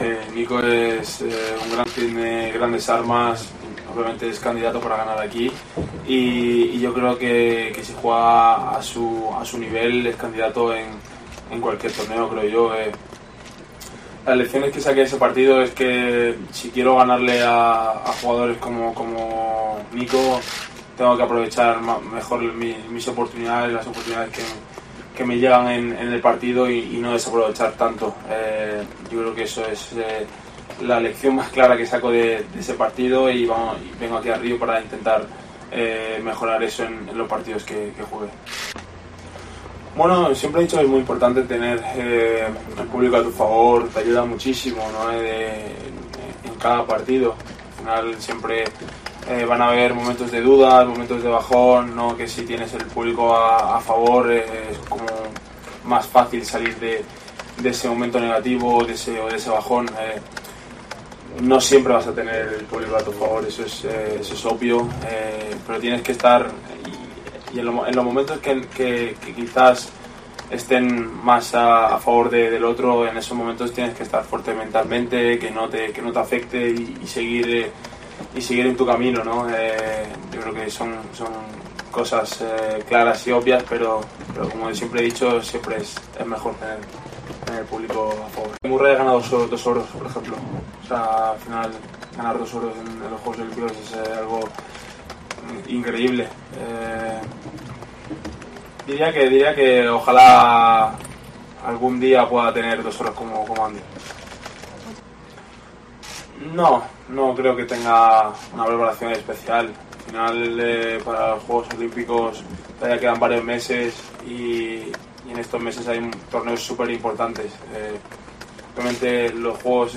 "Estoy muy feliz por la forma como Sinner viene jugando y ganando torneos", afirmó el murciano de 20 años en una rueda de prensa en Río de Janeiro, donde esta semana disputa el abierto de esta ciudad brasileña.